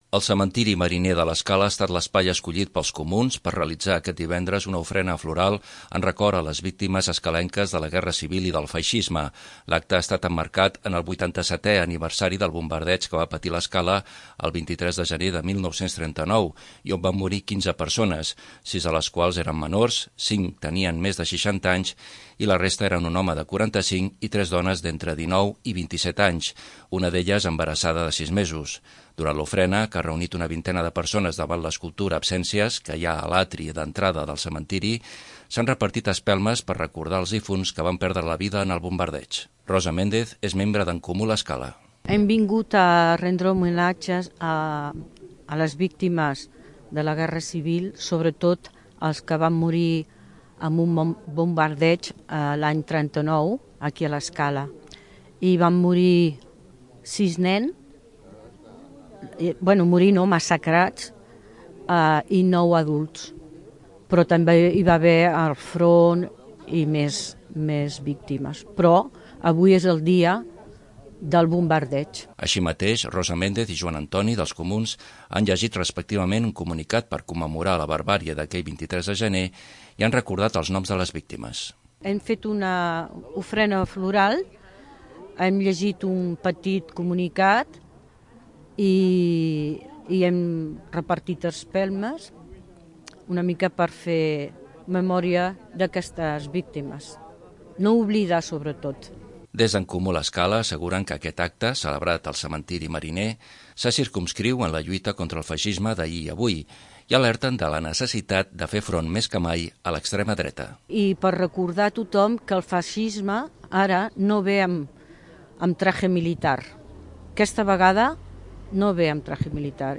Durant l'acte, celebrat al Cementiri Mariner, En Comú l'Escala ha alertat de la necessitat de continuar la lluita contra l'extrema dreta.